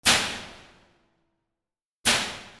for爆発素材D50